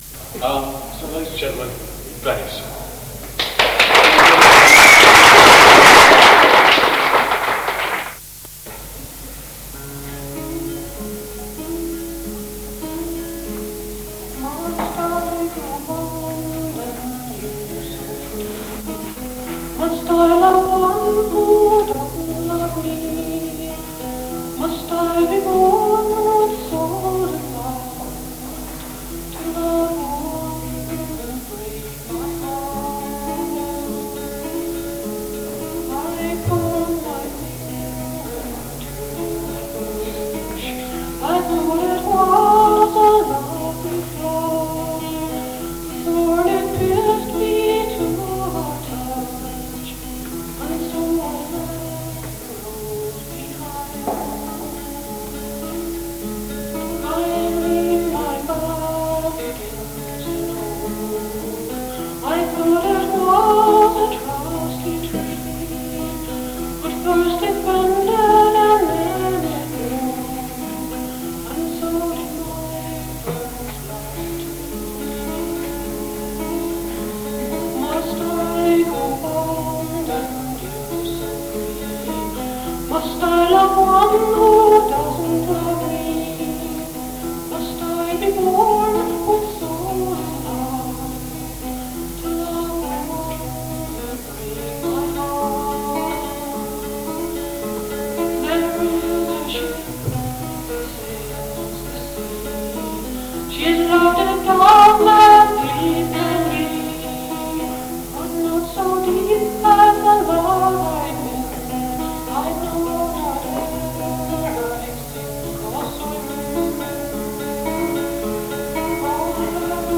Floor singers, and invited, recorded at Harvester's Folk Club,
City of Leicester College of Education, Scraptoft, Leicester during 1976
Recorded live 1976 Harvester's Folk Club, City of Leicester College of Edication, Scraptoft, Leicester by